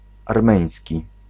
Ääntäminen
Ääntäminen France Tuntematon aksentti: IPA: /aʁ.me.njɛ̃/ Haettu sana löytyi näillä lähdekielillä: ranska Käännös Ääninäyte Substantiivit 1. ormiański {m} Adjektiivit 2. ormiański {m} 3. armeński Suku: m .